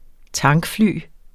Udtale [ ˈtɑŋg- ]